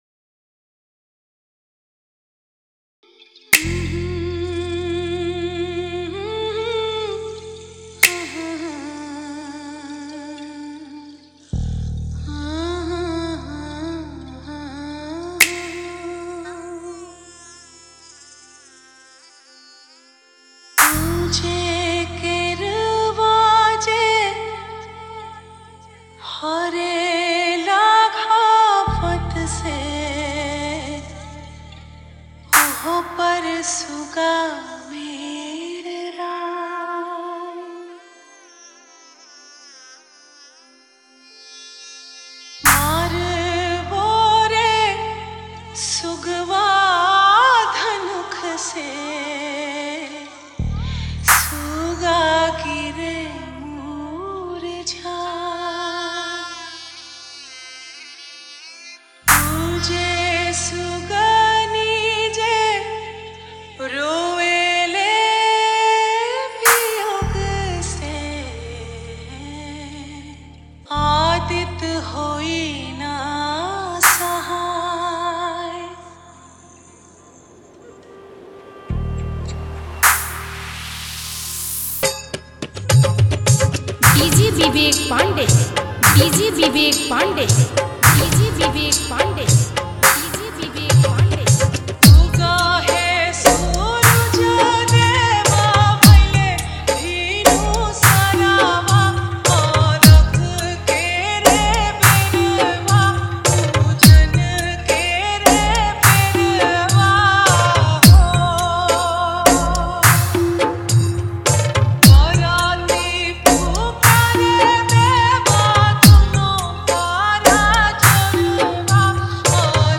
Bhojpuri Remix Mp3 Songs